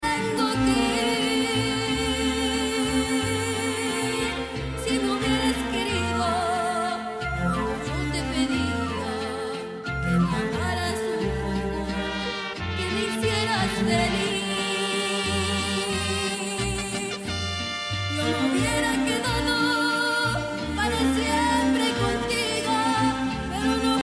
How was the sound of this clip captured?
Blending live Latin music with heart, filmed in Irving, TX.